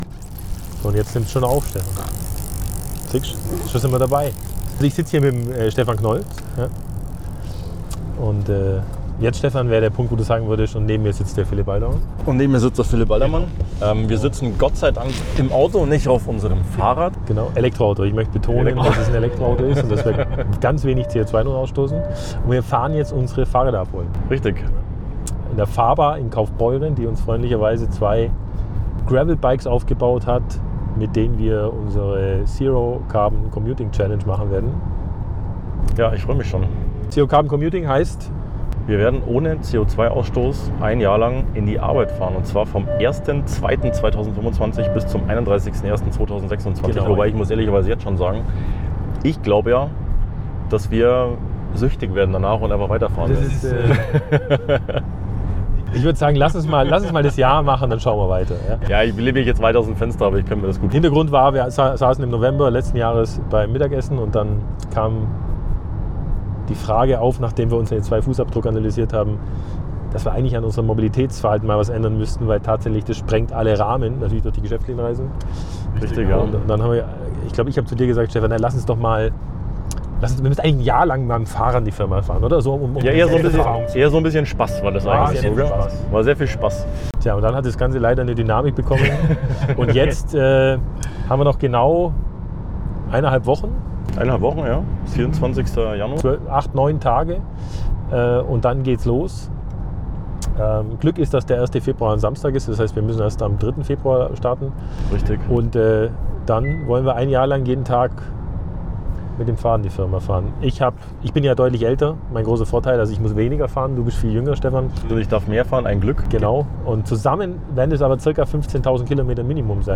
Noch eineinhalb Wochen: Wir holen unsere neuen Gravel-Bikes ab bei der FAHRBAR in Kaufbeuren und unterhalten uns über den Start unseres Experiments!